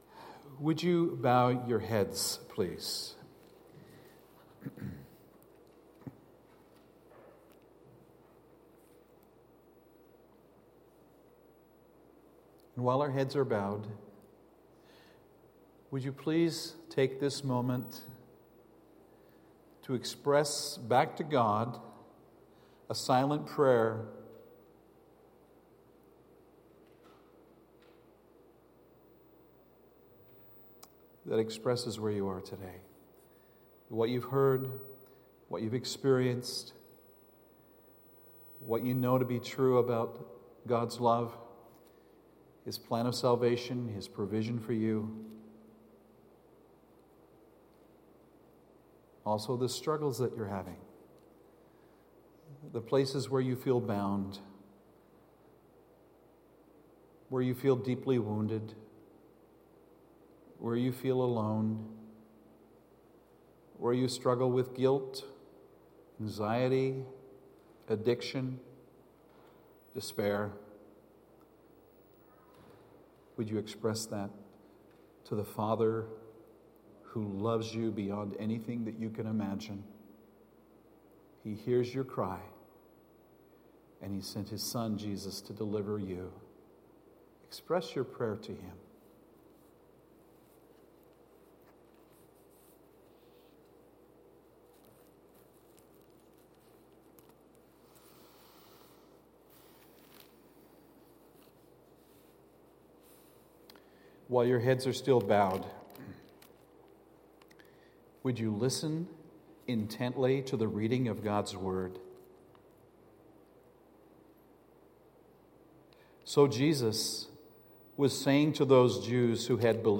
Sermons | Peninsula Mission Community Church